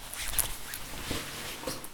trousers_01.ogg